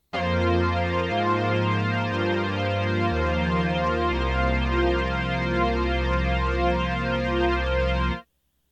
The phase-offset LFOs are a feature I exploit a lot on the SDD-3300.
Assorted Chorus mp3s
Stereo Sweep
memorymoog-polybrass-sdd3300-stereo-sweep.mp3